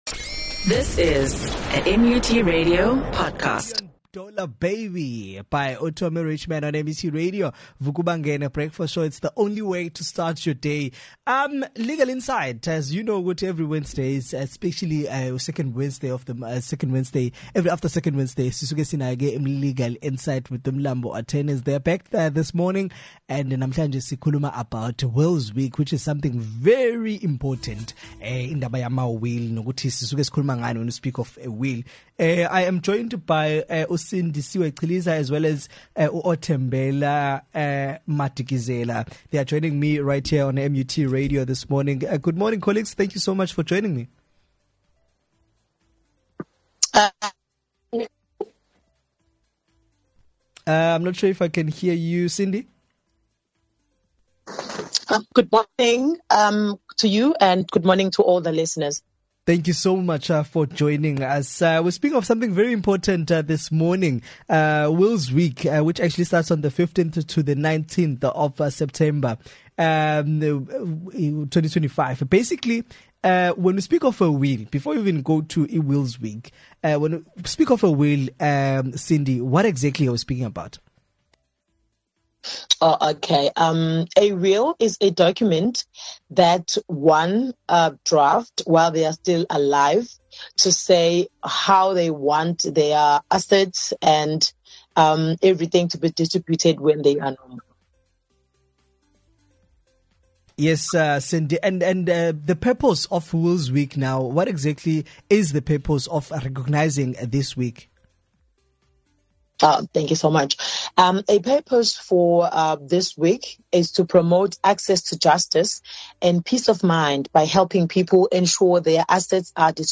had an interview